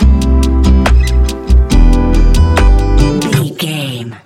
Ionian/Major
Lounge
sparse
new age
chilled electronica
ambient
atmospheric